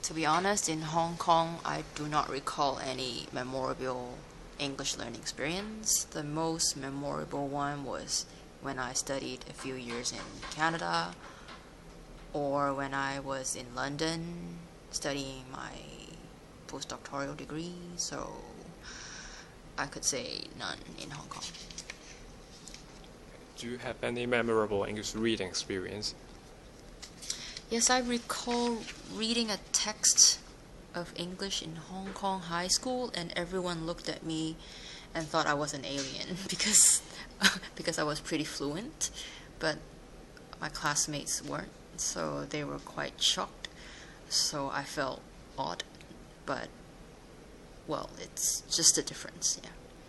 Subcategory: Reading, Speech, Travel